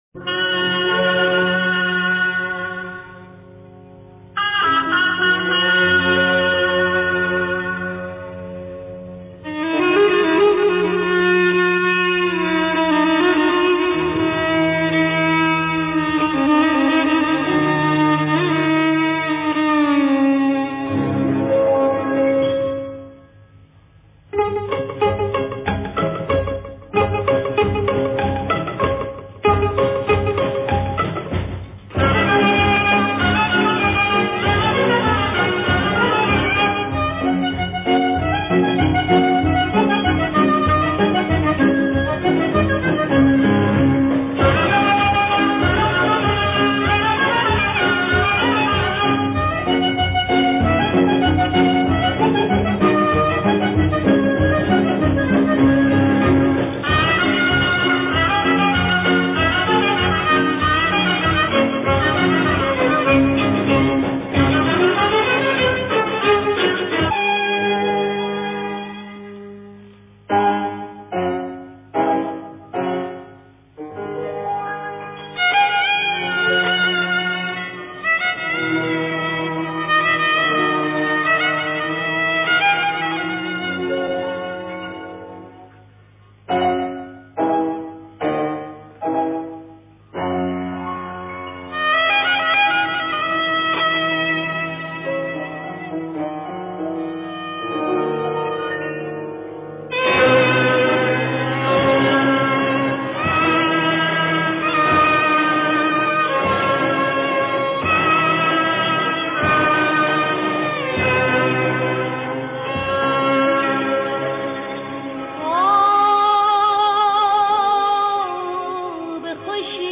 در مایه: اصفهان